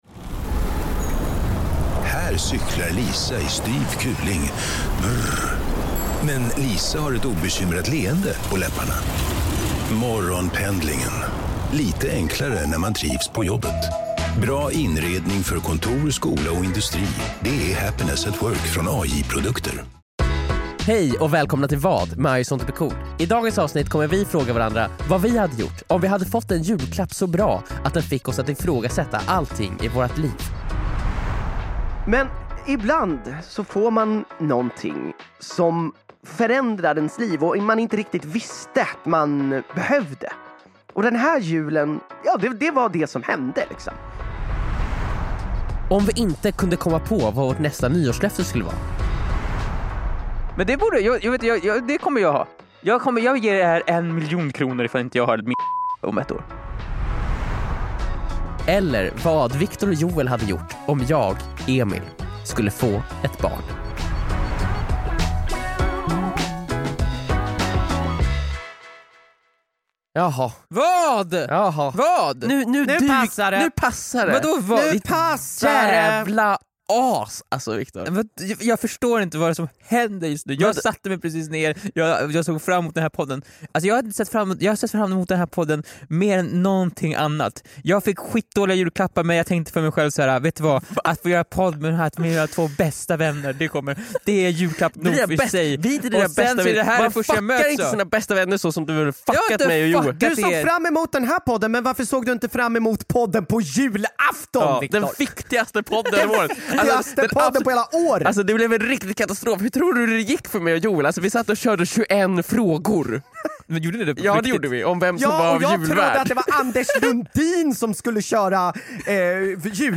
Vår alldeles första livepodd, från Bokmässan i Göteborg!